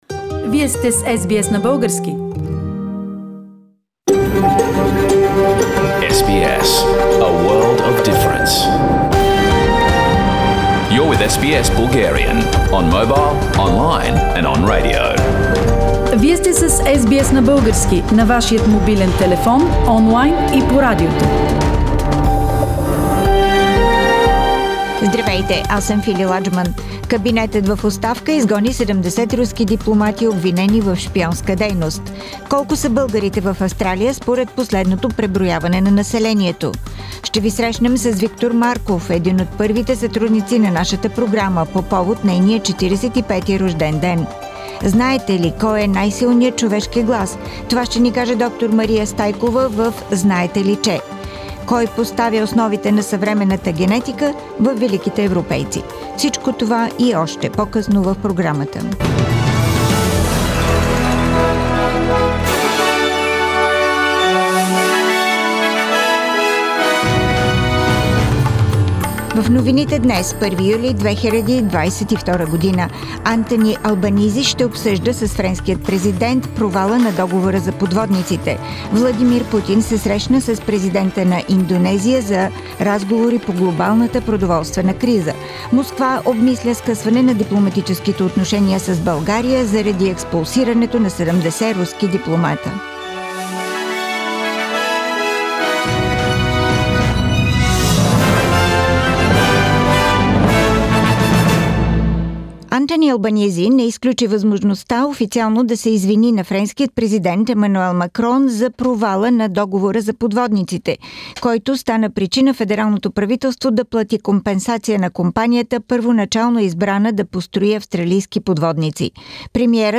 Weekly Bulgarian News – 1st July 2022